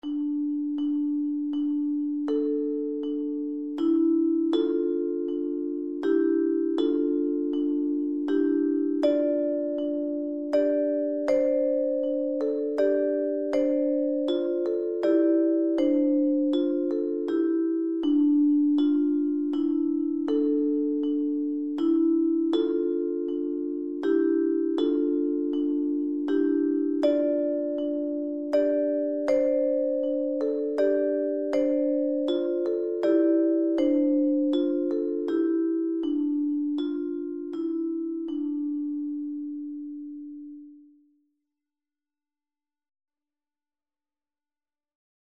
Kanon mit 2 zu 8 Stimmen, Einsatz in jedem Takt möglich
Canon met 2 tot 8 stemmen, invallen mogelijk in ieder maat
driestemmig